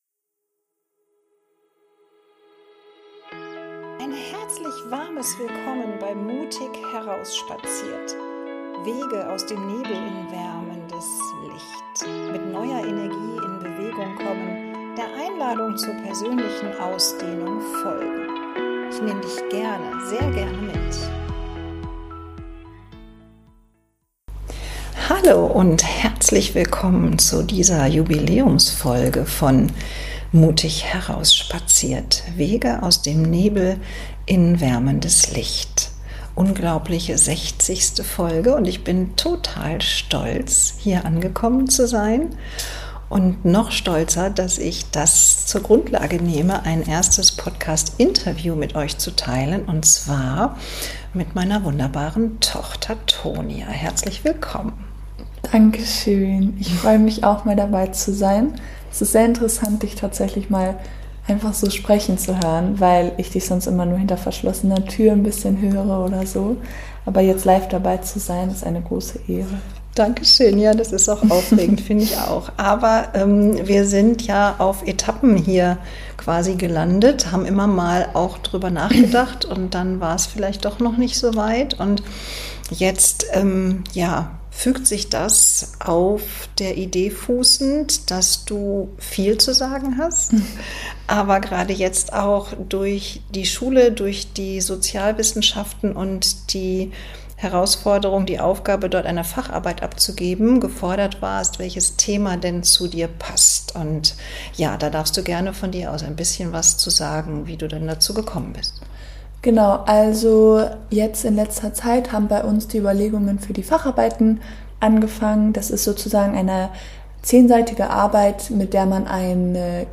Beschreibung vor 5 Monaten Ein sehr mutiger Schritt, ein ehrliches Eingeständnis, ein offenes Gespräch zwischen Mutter und Tochter. Essstörung und die sozialen Medien, der Algorithmus und das Unterbewusstsein.